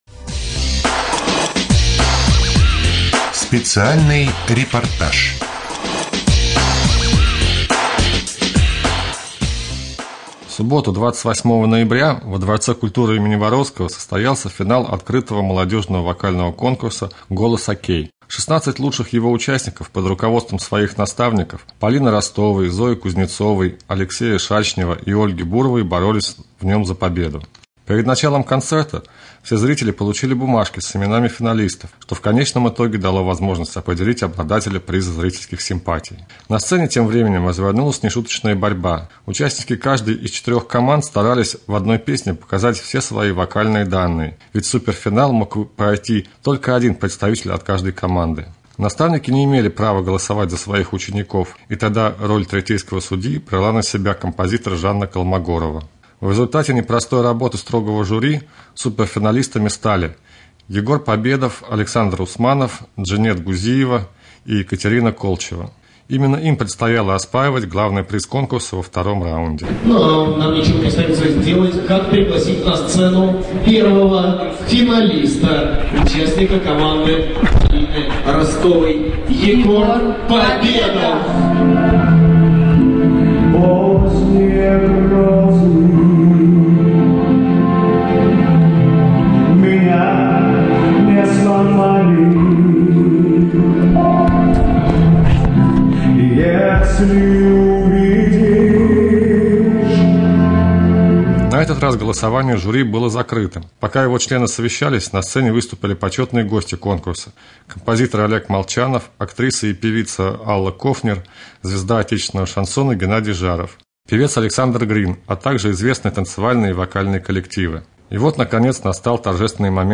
2.Рубрика «Специальный репортаж». В ДК им.Воровского состоялся финал Открытого молодежного фестиваля-конкурса «Голос ОК».